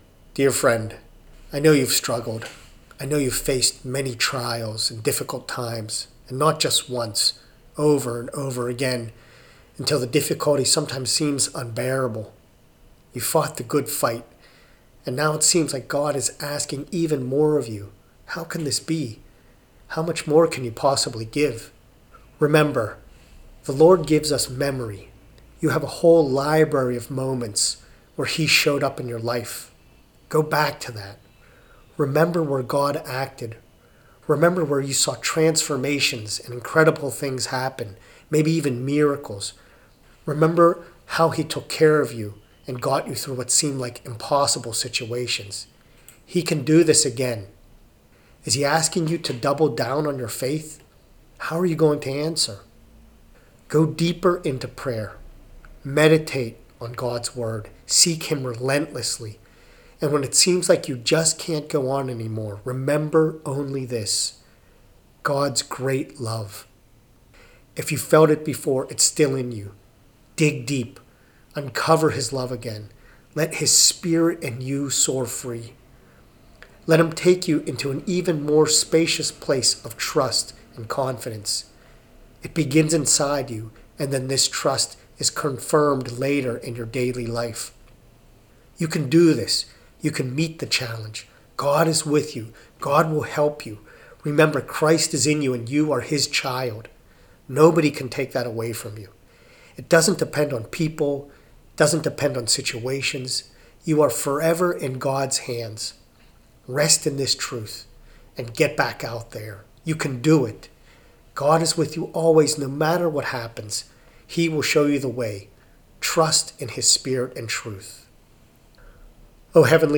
audio-prayer-for-deeper-strength.mp3